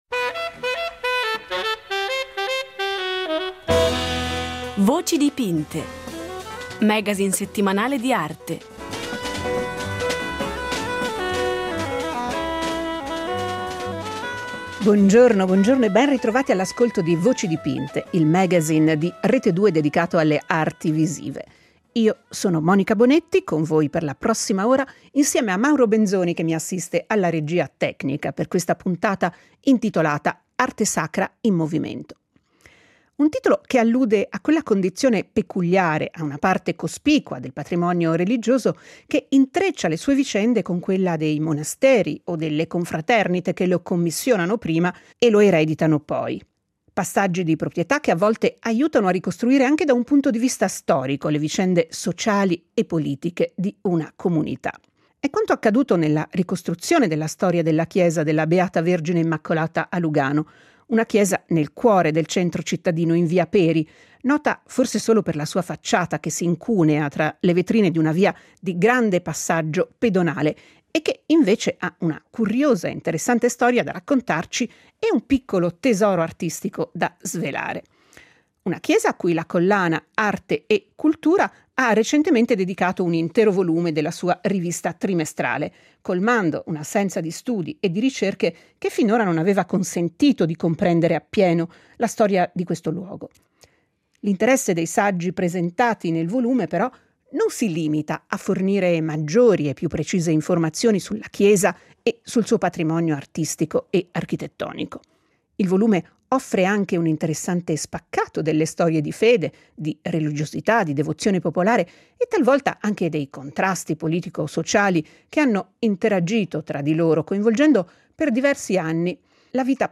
Nella seconda parte della puntata, vi proponiamo la quinta delle dieci conversazioni che abbiamo registrato con il pittore italiano Tullio Pericoli ;